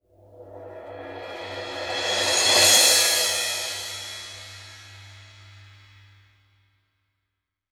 MALLETSON -R.wav